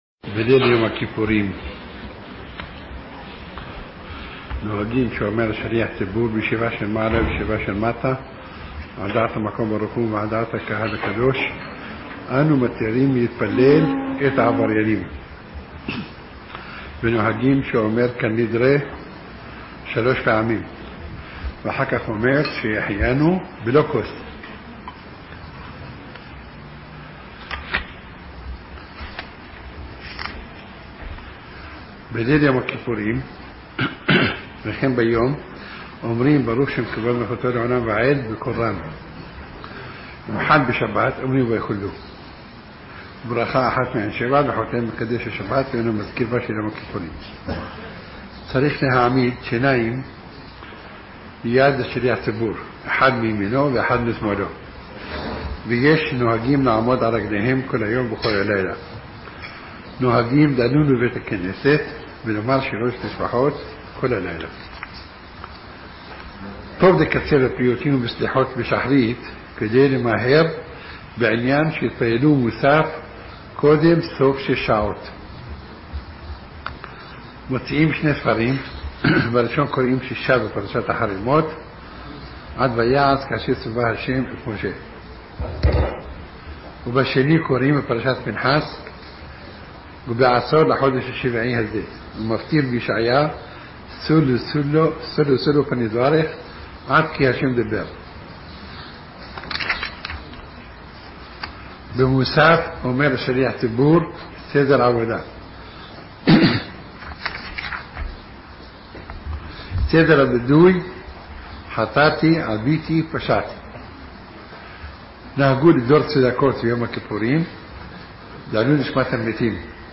הלכות יום כיפור: שיעור מיוחד ממרן רבינו עובדיה יוסף זיע״א, העוסק בתפילות יום הכיפורים, סדרן ומשמעותן